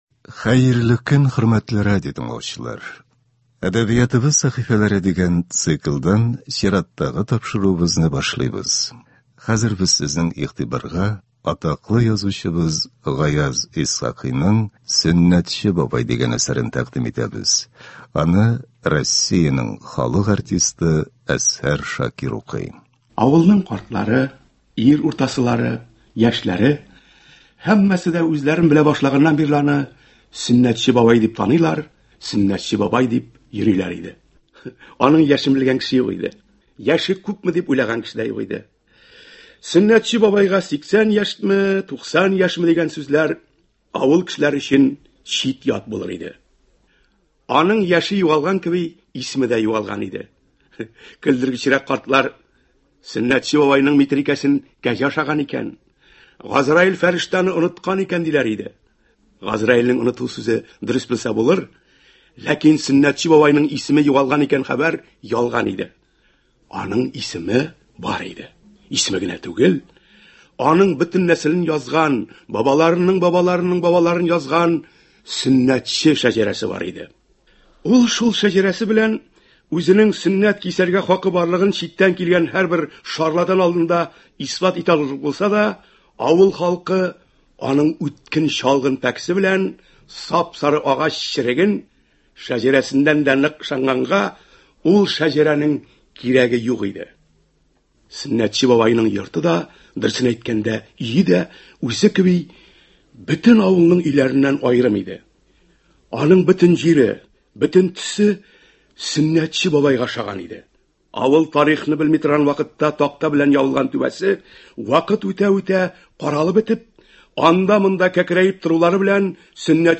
Моноспектакль.
Игътибарыгызга Гаяз Исхакыйның «Сөннәтче бабай» дигән хикәясен тәкъдим итәбез. Ул Россиянең халык артисты Әзһәр Шакиров башкаруында яңгырый.